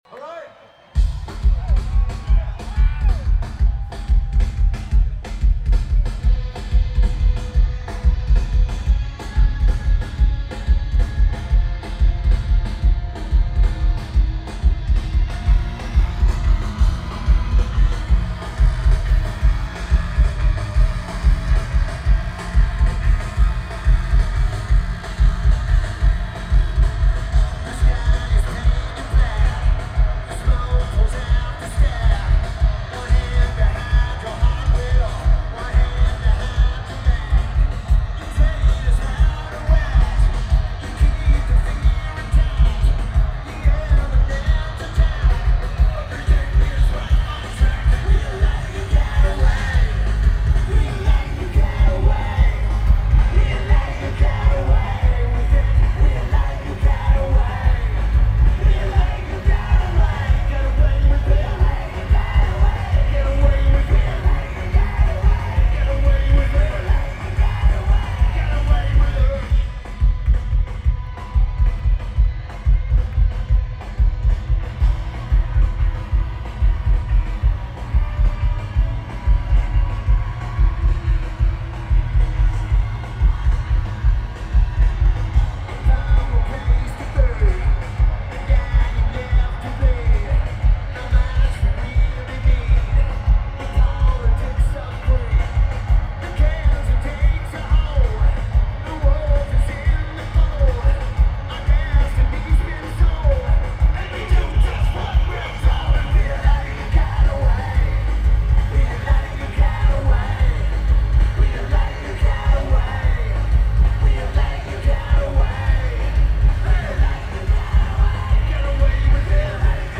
Red Rocks Amphitheatre
Lineage: Audio - AUD (Olympus LS-P4)